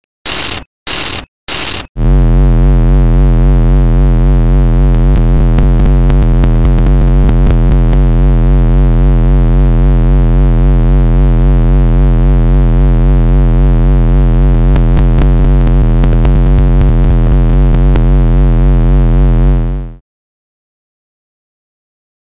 SFX干扰音效下载
SFX音效